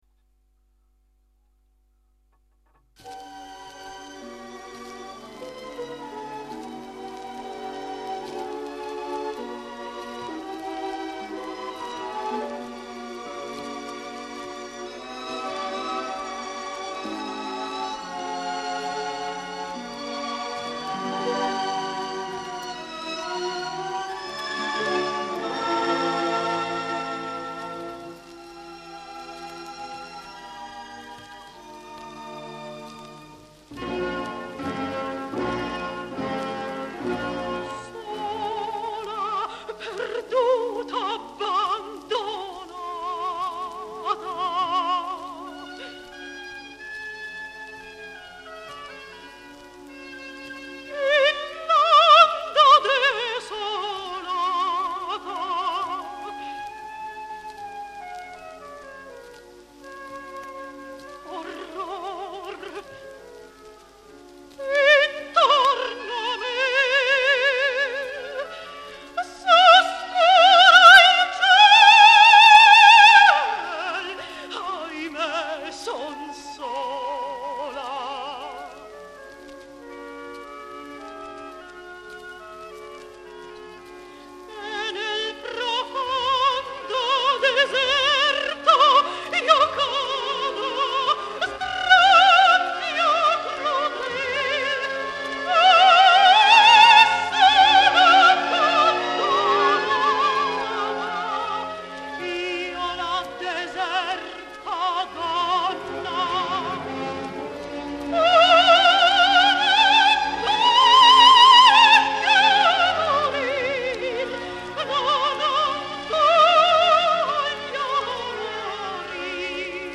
OPERATIC ARIAS  [  BACK ]